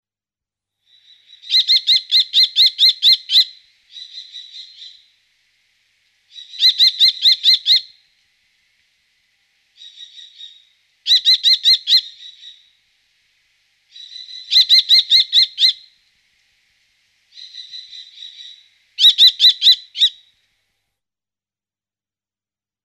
Chant :
Faucon crécerelle
Le faucon crécerelle a un cri caractéristique, lancé d'un ton haut perché et querelleur " kii-kii-kiikii " quand il est en alerte, et un bref " kik-kik " en vol normal.
Quand les jeunes sont au nid et les parents à proximité, le contact s'établit par un " ki-yiyi " aigu et souvent répété.
D'autres sons, plus courts et âpres, peuvent être entendus, exprimant l'anxiété, ou sont émis près ou à l'intérieur du nid.
03Kestrel.mp3